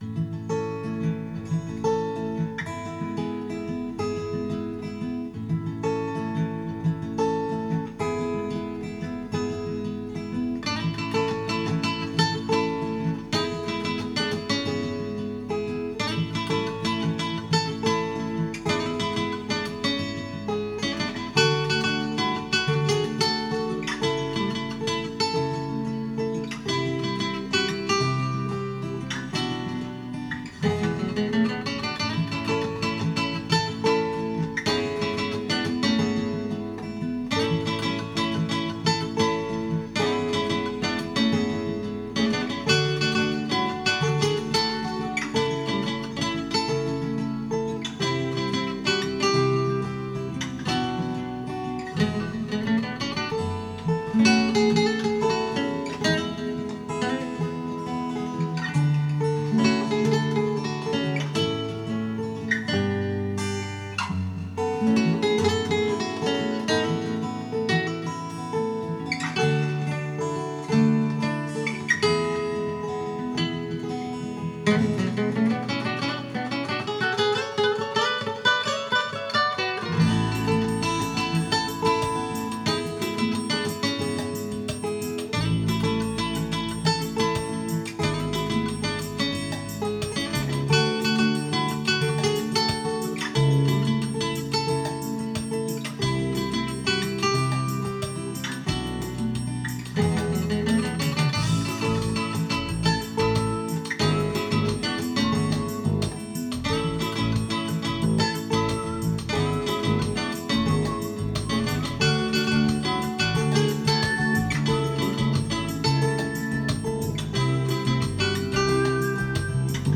X-Yステレオマイクロホン AT822
CDの音楽をスピーカーから再生し、
DR-100で96kHz24bitと48kHz16bitで録音しました。
スピーカーは、DS-500とYST-SW50です。